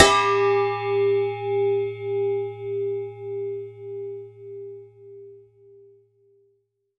bell_med_ringing_03.wav